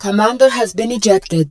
marine_commander_ejected.wav